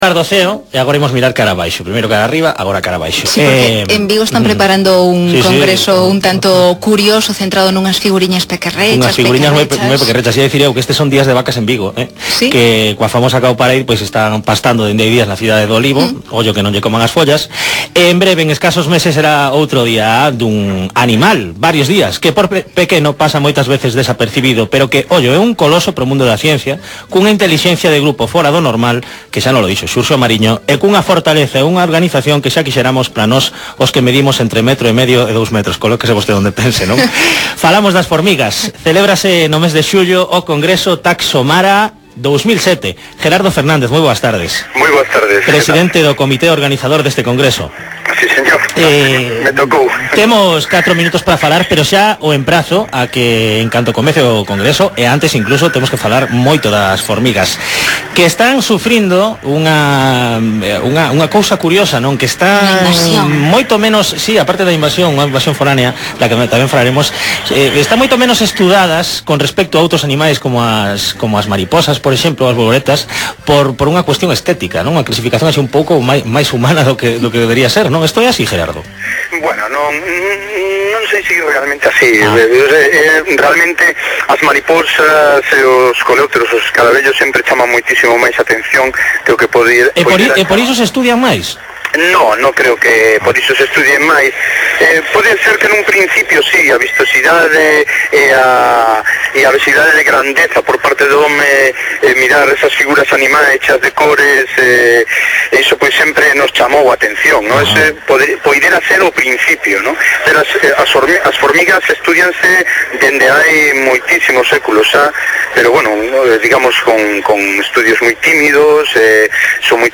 1ª Entrevista Radiófonica Taxomara 2007